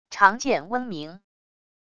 长剑嗡鸣wav音频